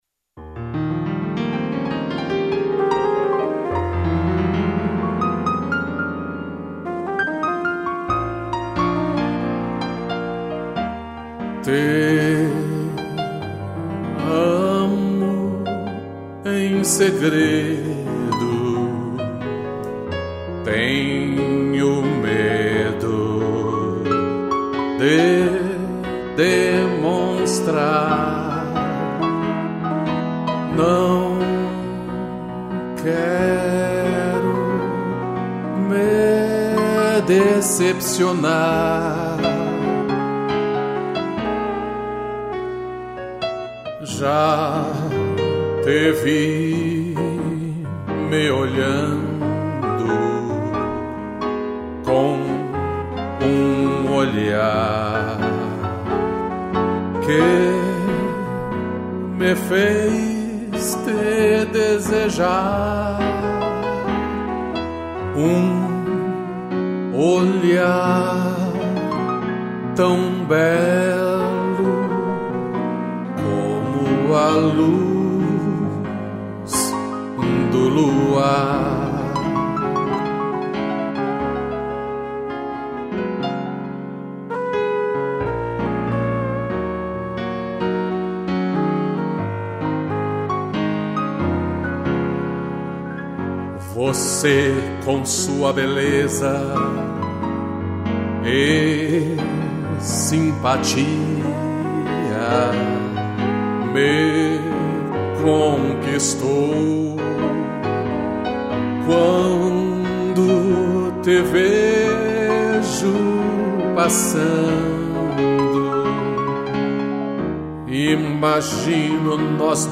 2 pianos e trompete